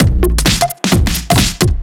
OTG_DuoSwingMixB_130a.wav